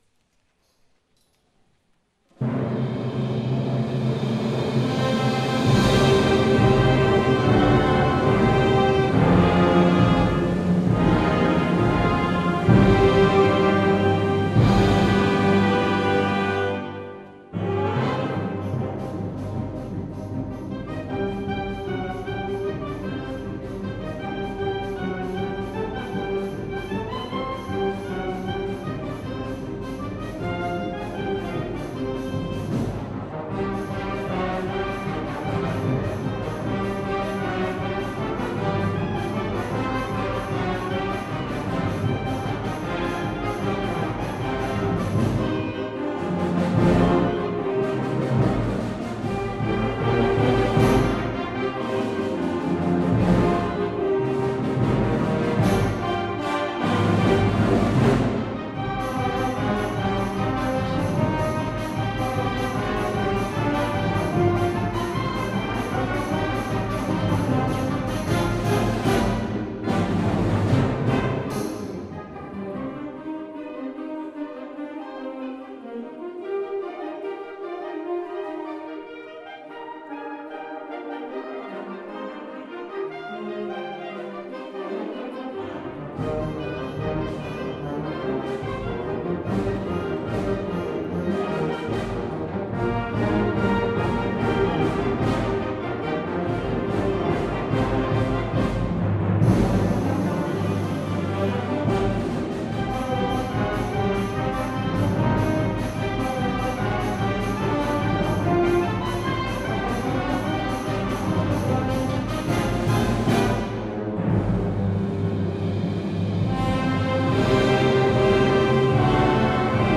Ecco il nostro concerto di Primavera!
Tramite i links sottostanti è possibile scaricare i files audio del nostro concerto di primavera, registrati in live durante il concerto.